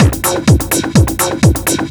DS 126-BPM B4.wav